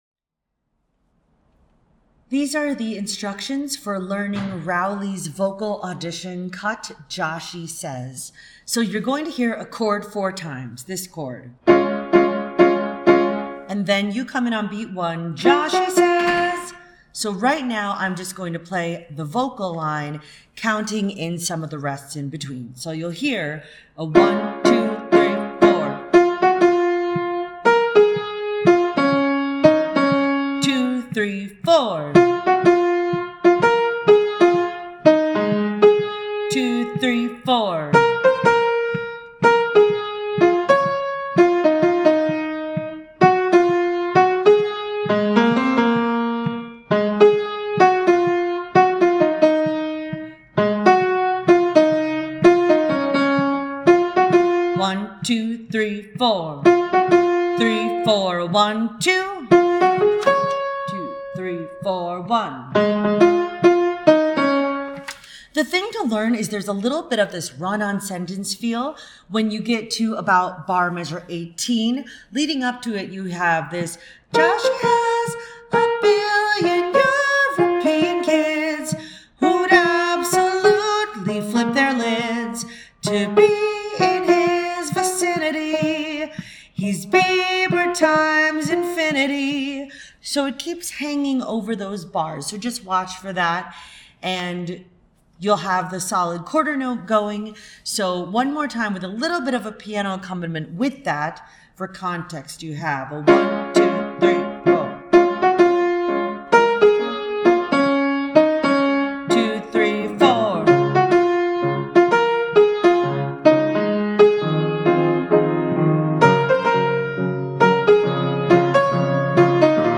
Track with vocal